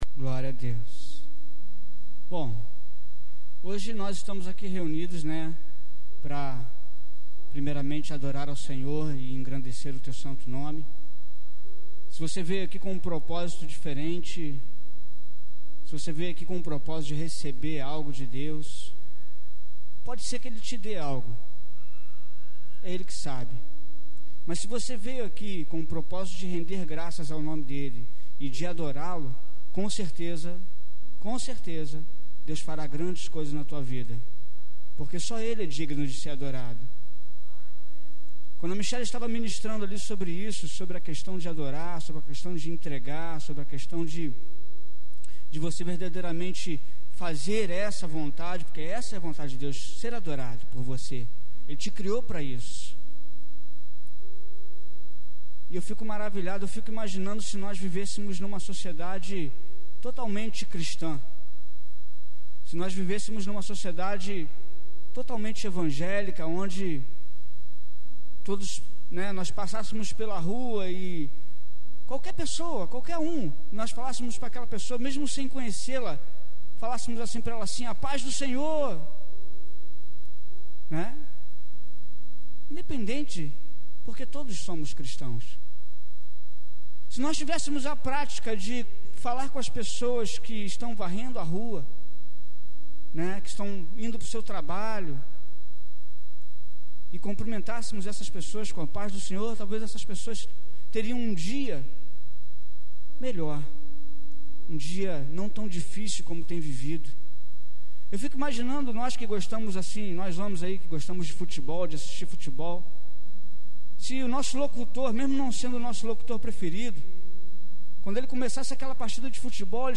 culto_domingo_15_7_12b.mp3